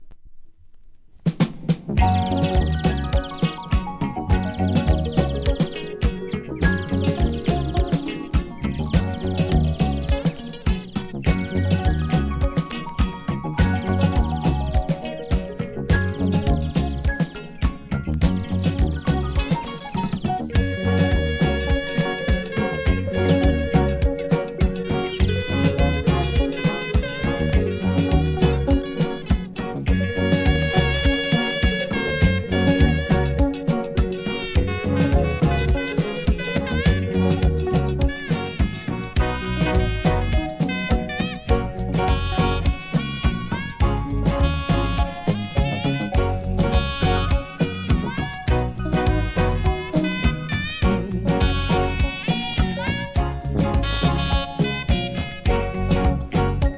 REGGAE
竹笛バンブー・サックスでのINST物!!